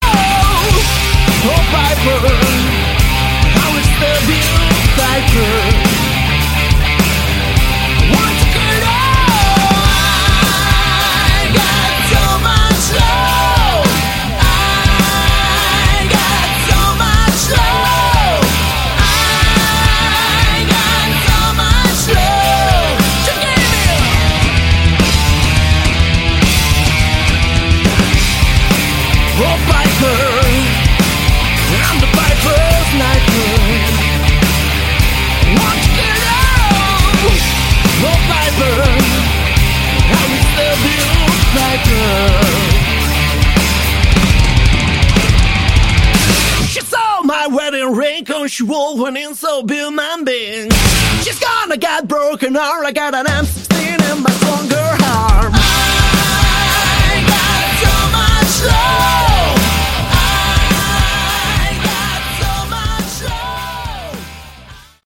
Category: Melodic Hard Rock
vocals
lead, rhythm, and acoustic guitars, keyboards
bass
drums